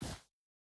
Shoe Step Snow Medium B.wav